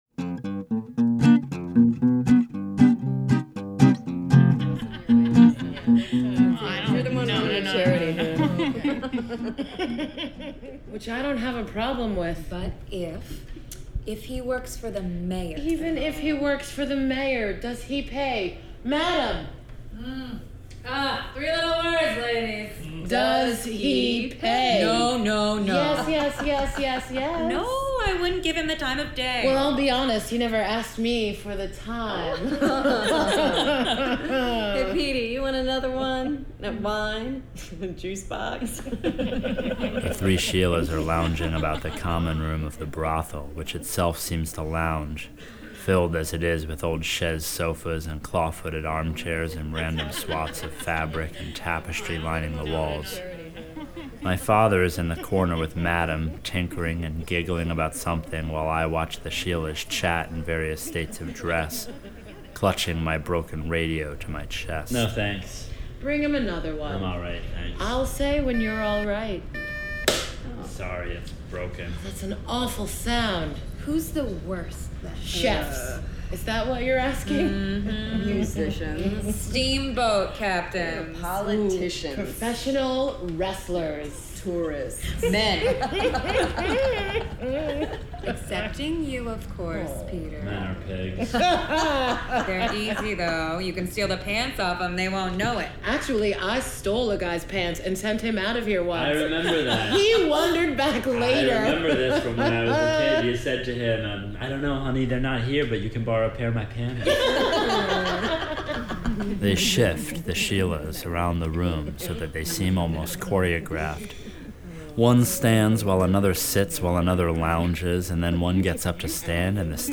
As we finalize production, we’ve been talking a lot about radio as a storytelling medium — what about it is so effective for us, and who else is having as much fun with it as we are?